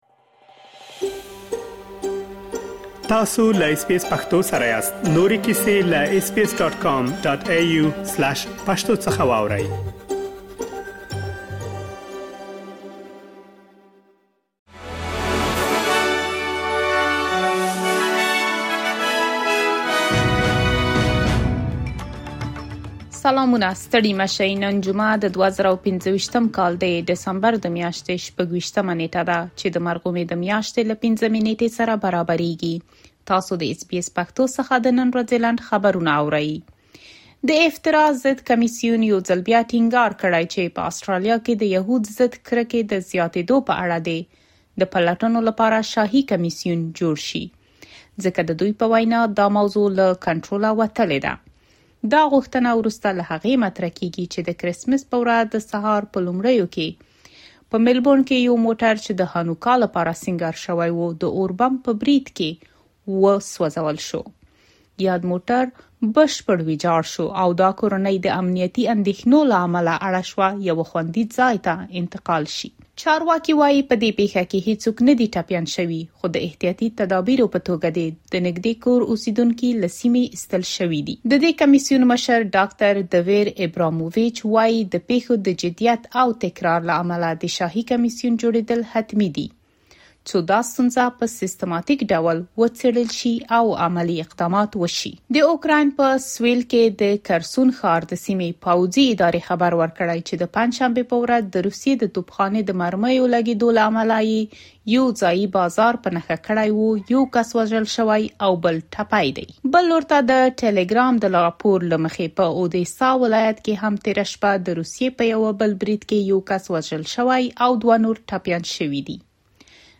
د اس بي اس پښتو د نن ورځې لنډ خبرونه |۲۶ ډسمبر ۲۰۲۵